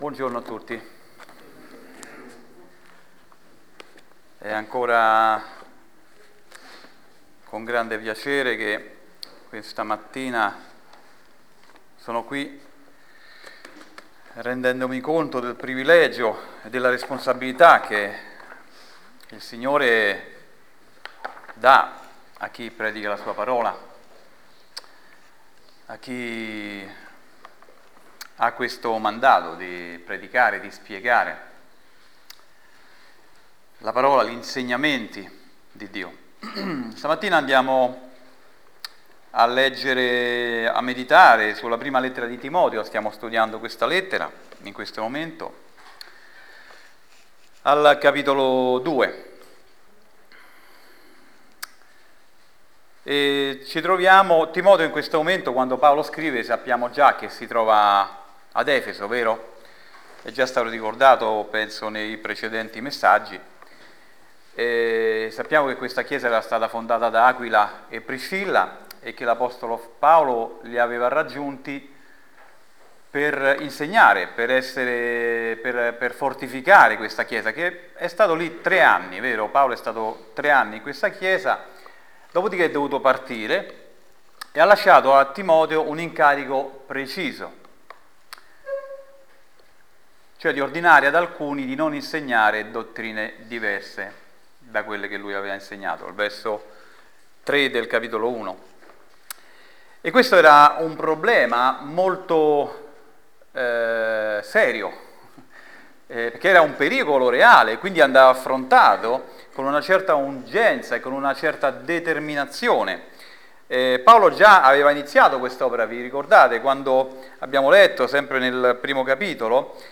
Insegnamenti dal passo di 1 Timoteo 2:1-8